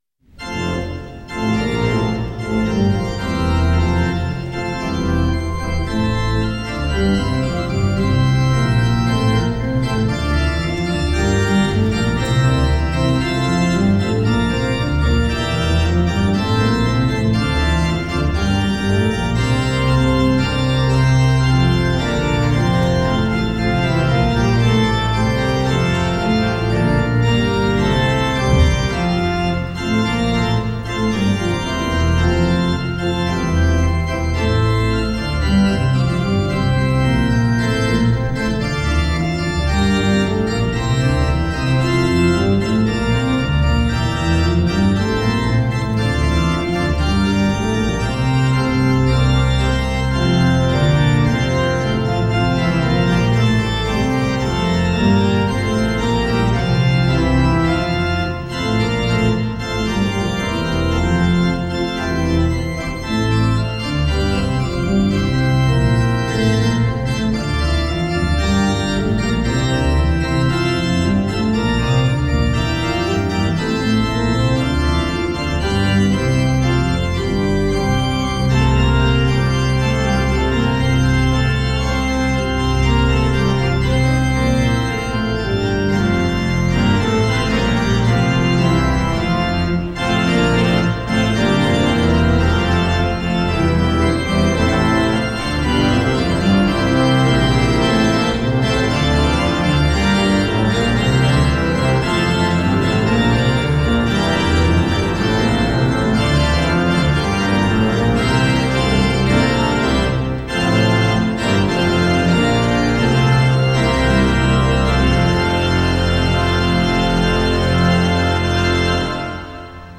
Hear the Bible Study from St. Paul's Lutheran Church in Des Peres, MO, from March 16, 2025.